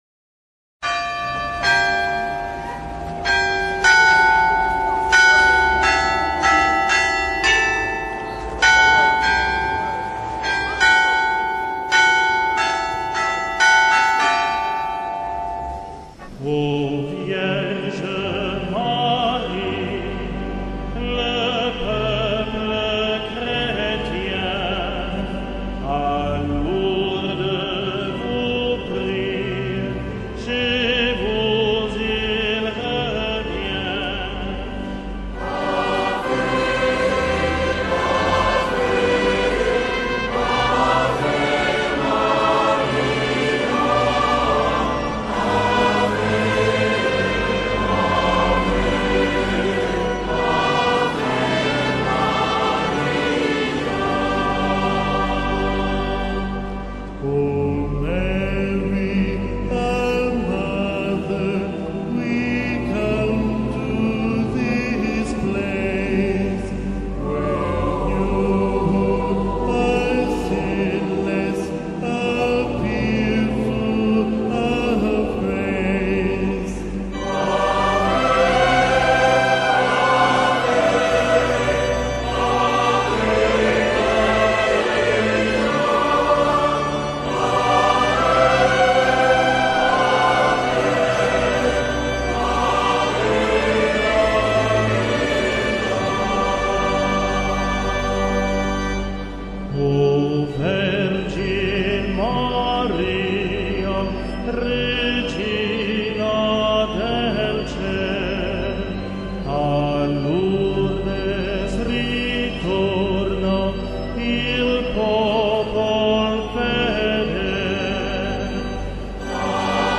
LOURDES-Ave-Maria-de-Lourdes-Salve-Regina.mp3